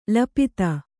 ♪ lapita